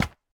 resin_brick_step1.ogg